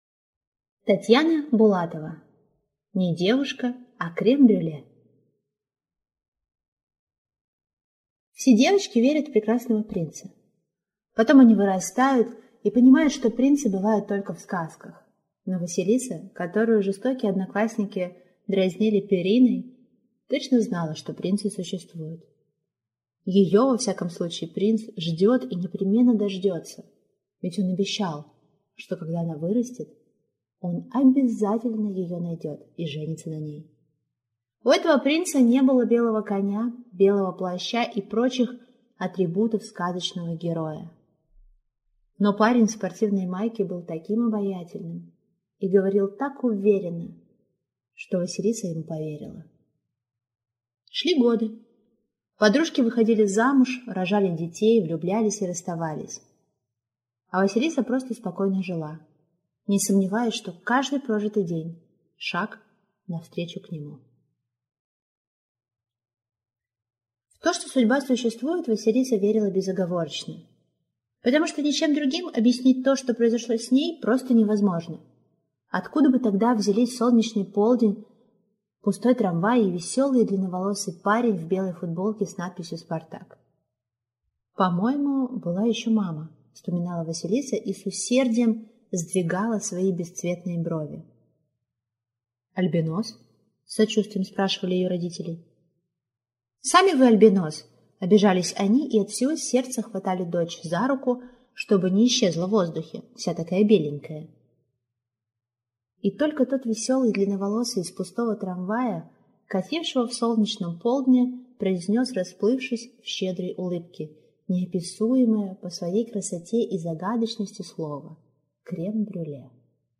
Аудиокнига Не девушка, а крем-брюле | Библиотека аудиокниг
Прослушать и бесплатно скачать фрагмент аудиокниги